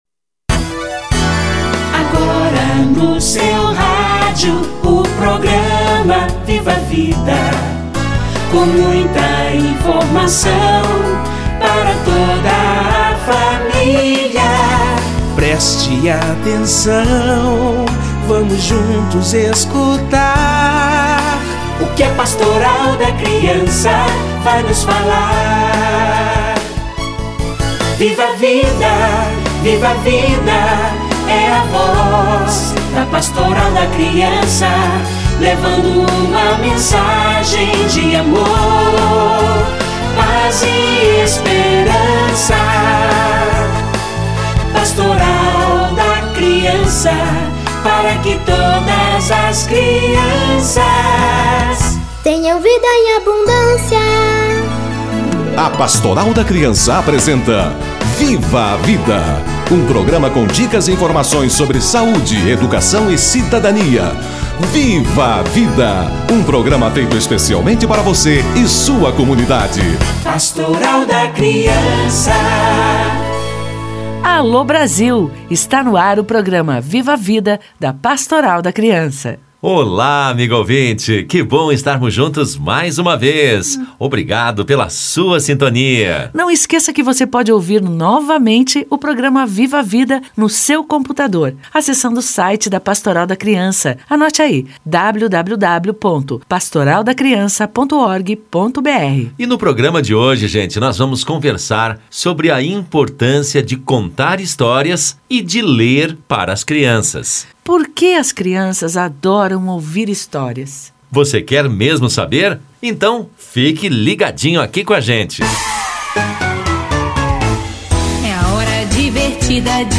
Leitura para as crianças - Entrevista